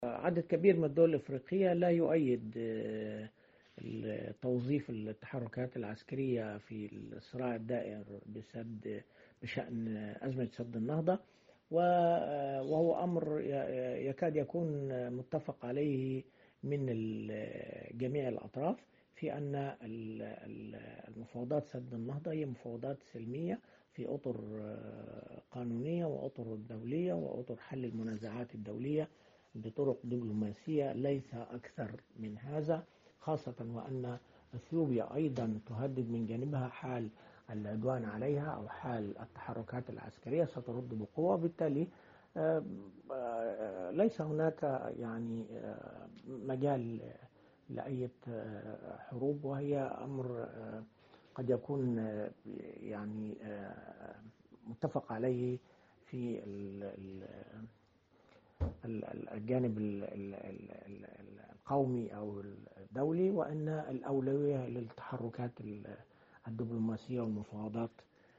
كاتب صحفي ومحلل سياسي